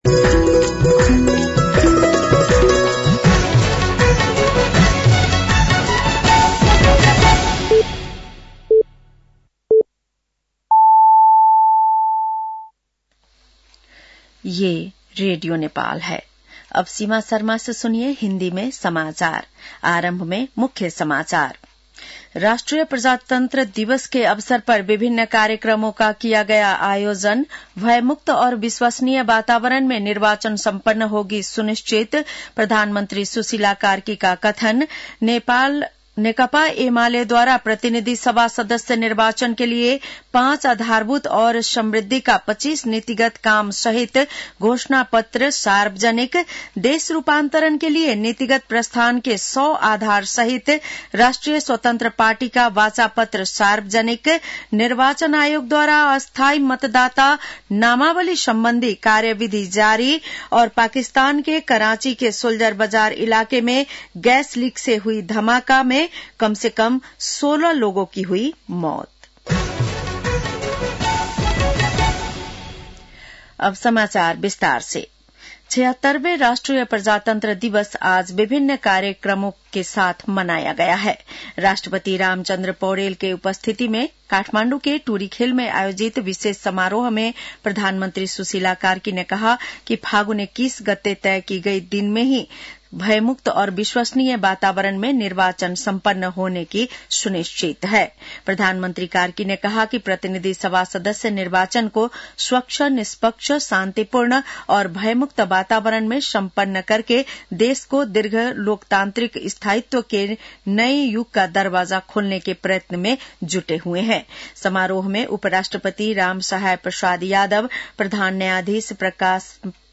बेलुकी १० बजेको हिन्दी समाचार : ७ फागुन , २०८२